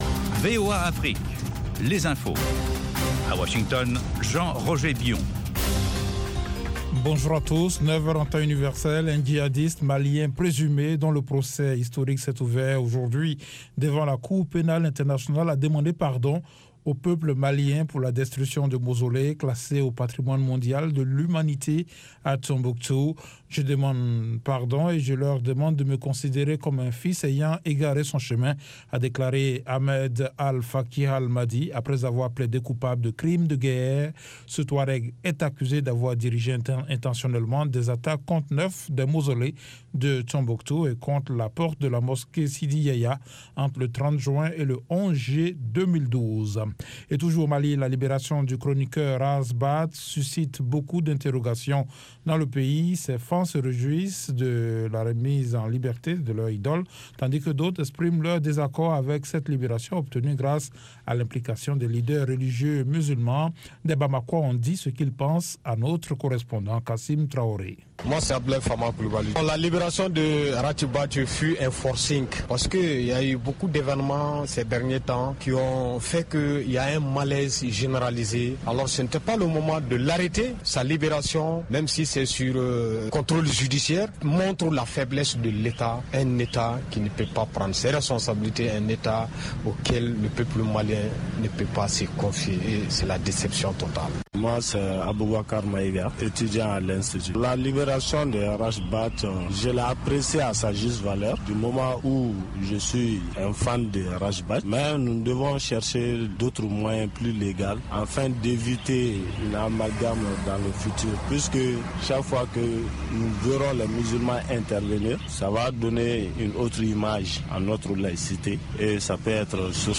Bulletin